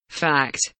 fact kelimesinin anlamı, resimli anlatımı ve sesli okunuşu